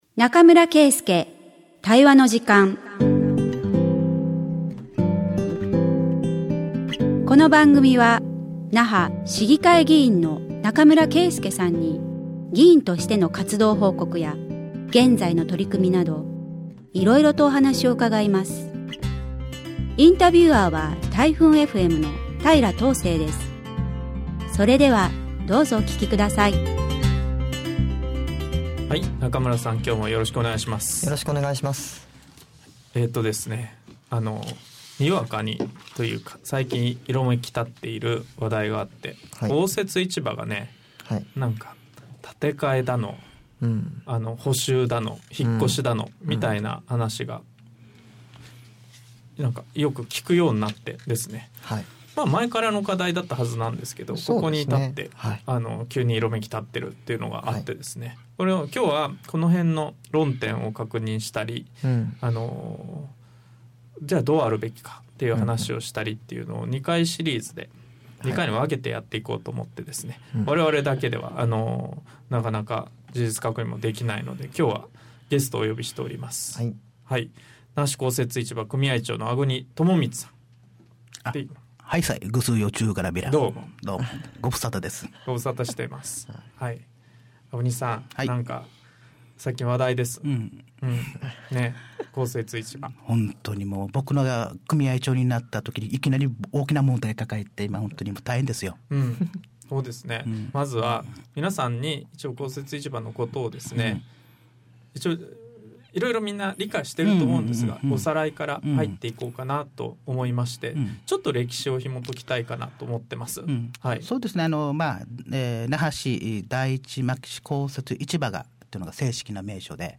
那覇市議会議員中村圭介が議員活動や現在の取組みを語る20分